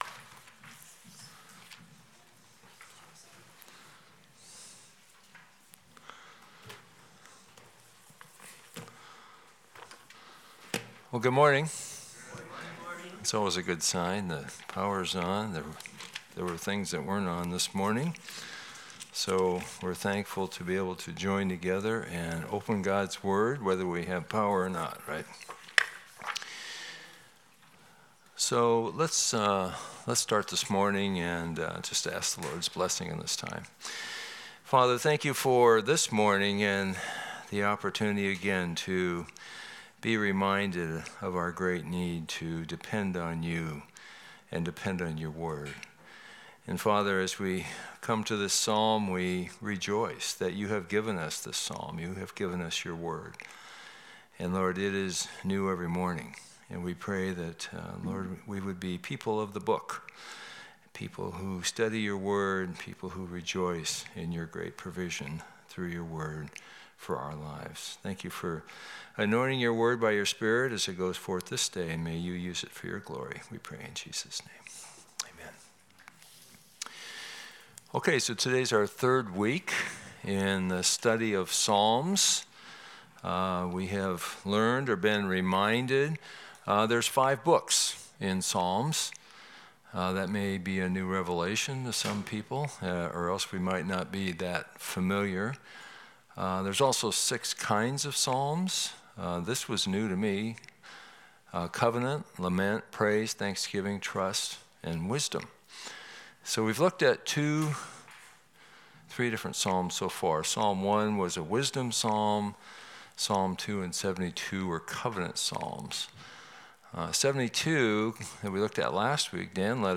Passage: Psalm 89 Service Type: Sunday School